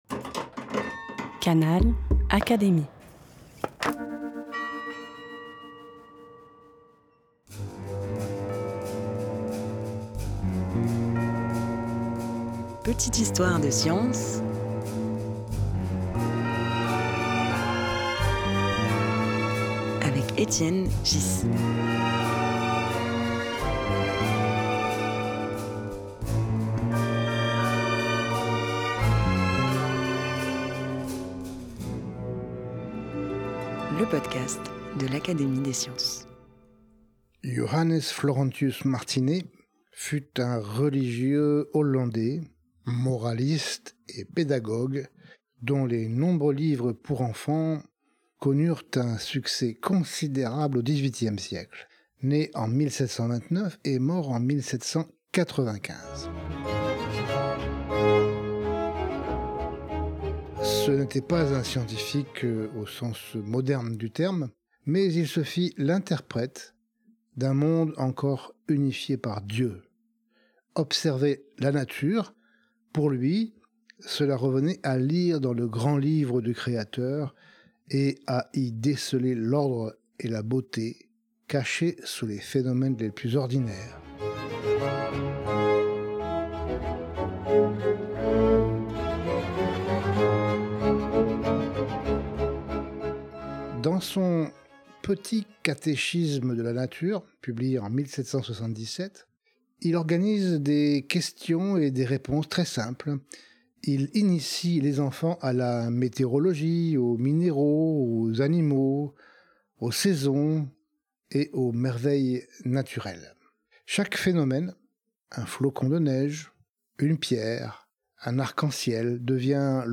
La lecture d’un extrait du Petit catéchisme de la nature de Johannes Florentius Martinet, pédagogue hollandais dont les ouvrages connurent un immense succès auprès des enfants, en offre un éclairant témoignage.